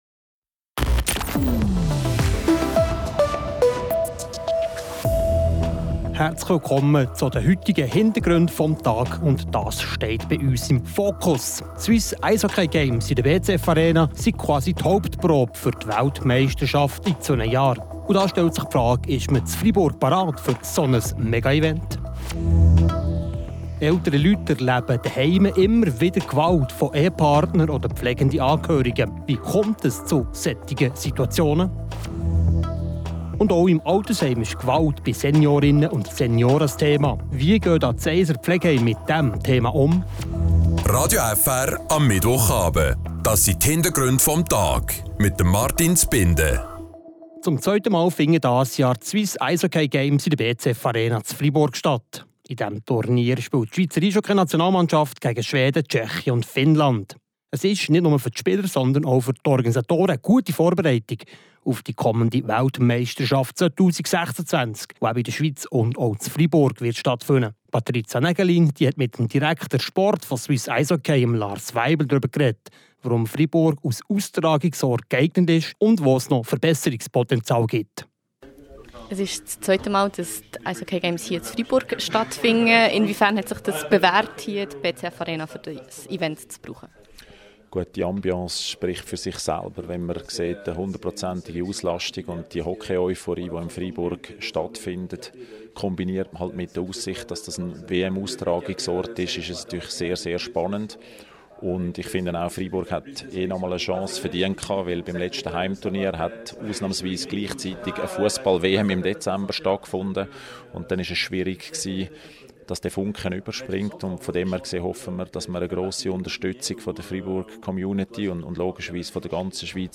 Ein Interview
Eine Sozialarbeiterin von Pro Senectute erklärt.